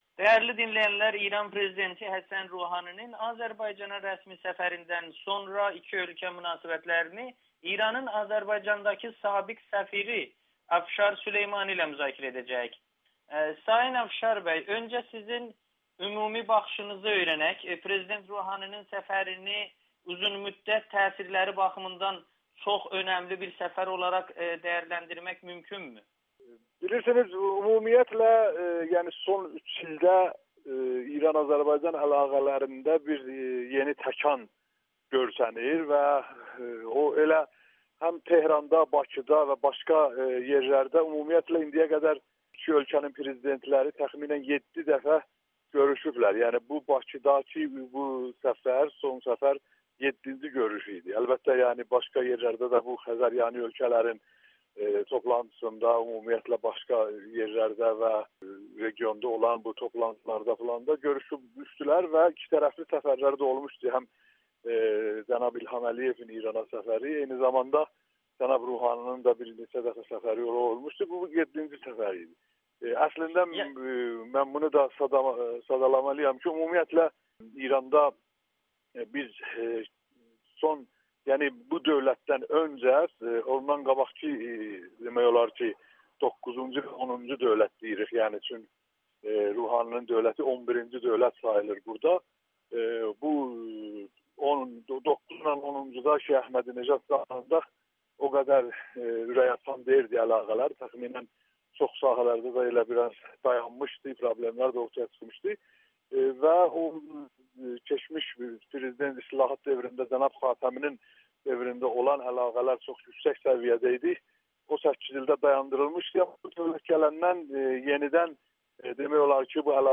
Afşar Süleymani: Azərbaycan-İran əlaqələrində dönüş nöqtəsi olub [Audio-Müsahibə]